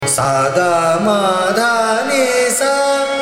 ThaatBhairavi
ArohaS g m d n S’
Malkauns (Aroha)